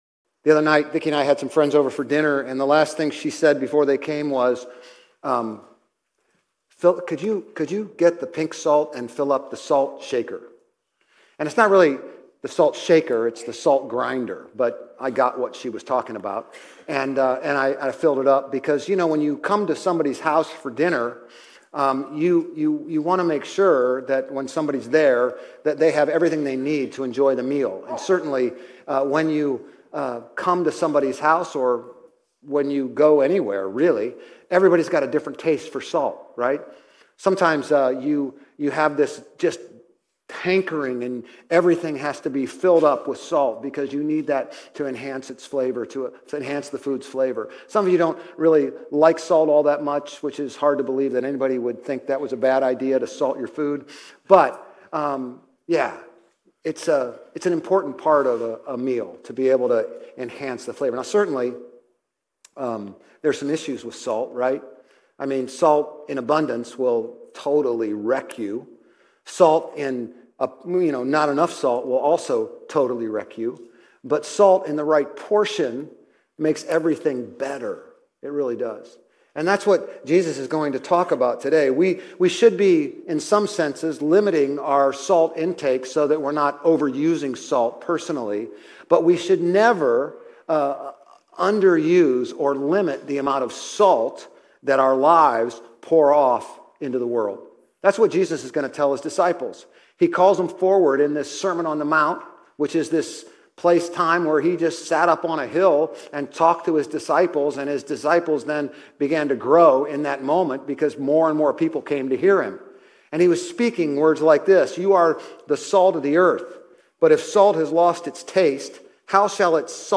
Grace Community Church Old Jacksonville Campus Sermons 2_2 Old Jacksonville Campus Feb 03 2025 | 00:26:13 1x 00:00 / 00:26:13 Subscribe Share RSS Feed Share Link Embed